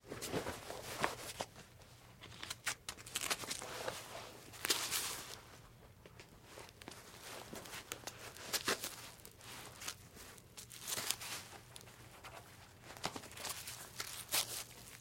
Звук: поліцейський одягає бронежилет